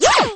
girl_toss_shock.wav